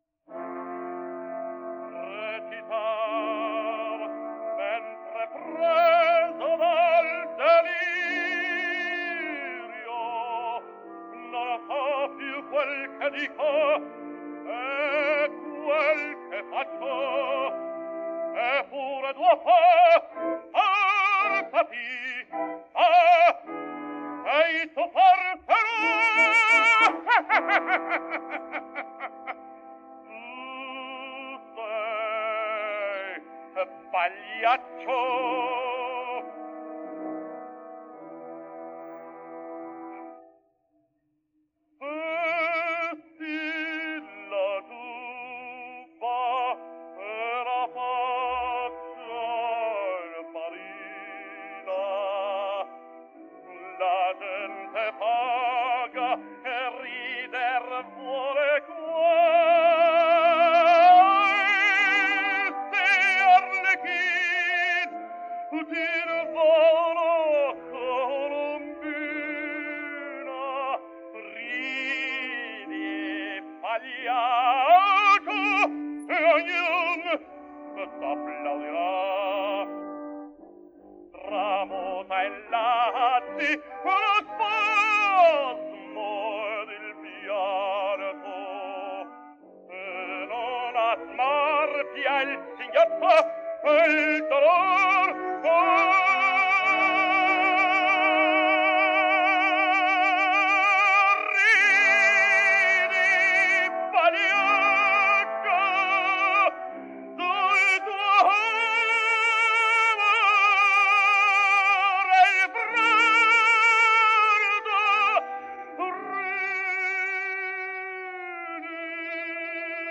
Index of /publications/papers/dafx-babe2/media/restored_recordings/caruso_giubba
denoised.wav